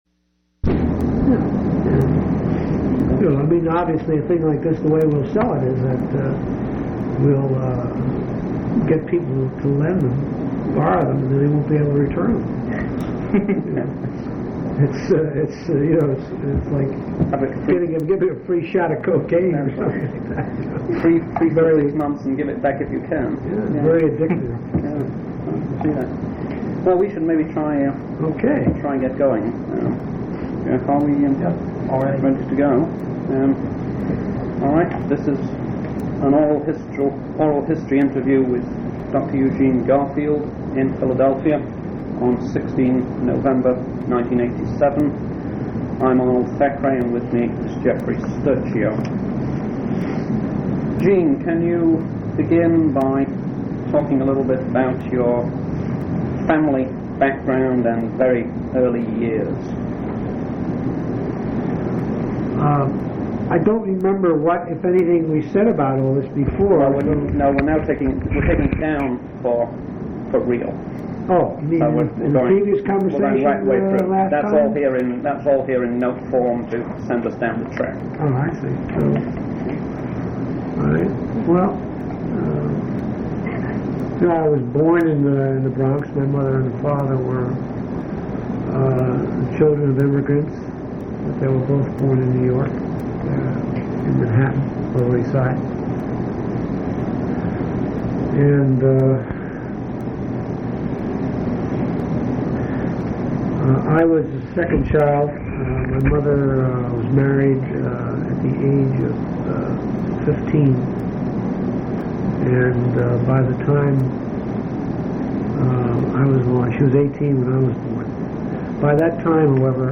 Oral history interview with Eugene Garfield